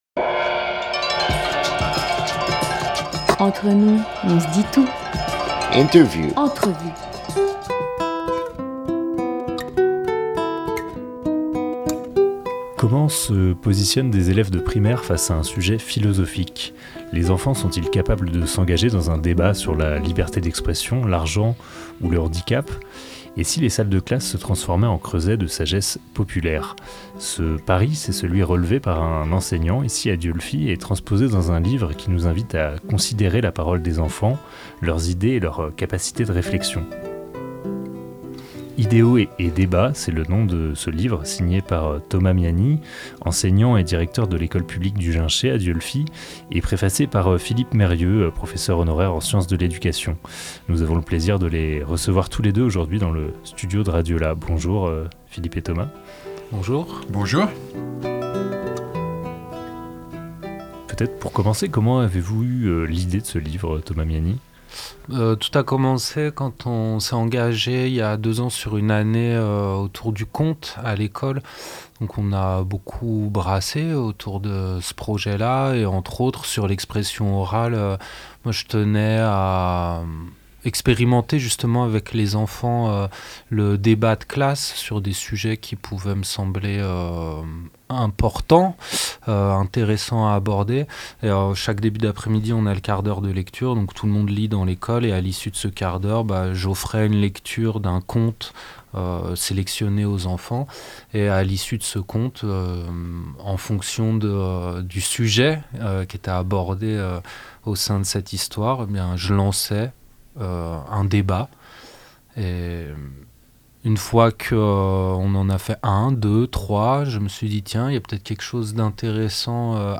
2 janvier 2023 11:30 | Interview